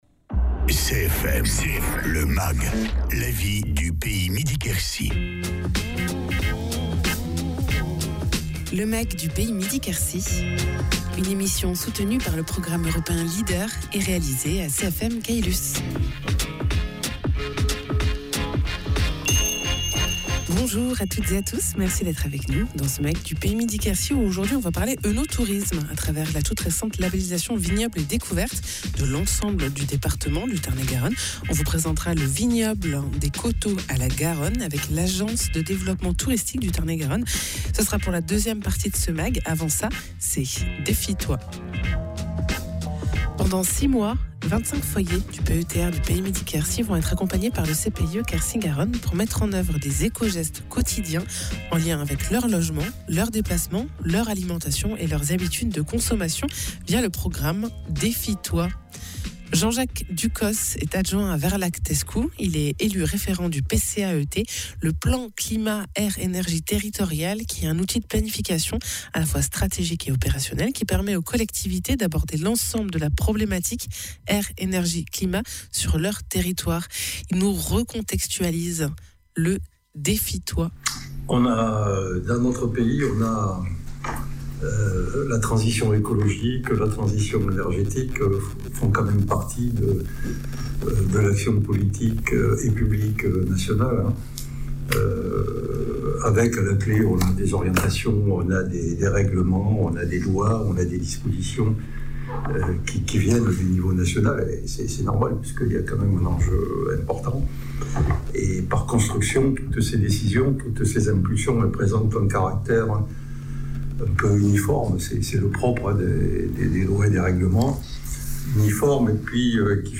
Invité(s) : Jean-Jacques Ducos, élu référent du PCAET du PETR Midi-Quercy